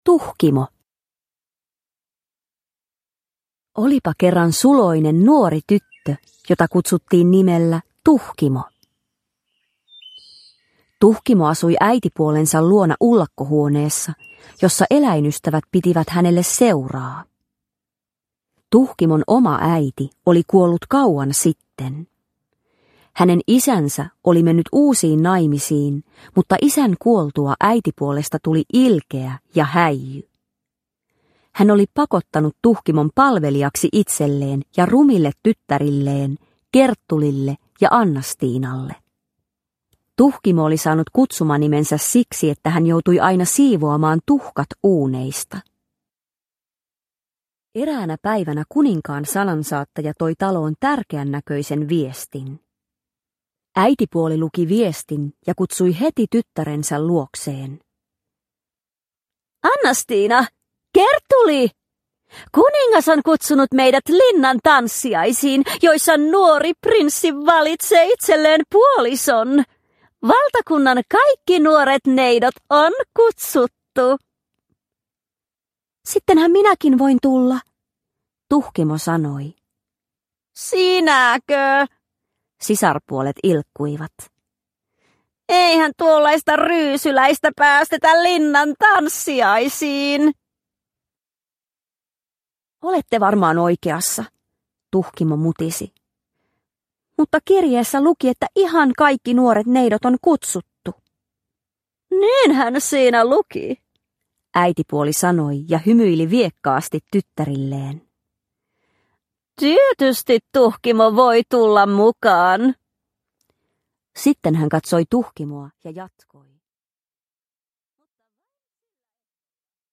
Tuhkimo – Ljudbok – Laddas ner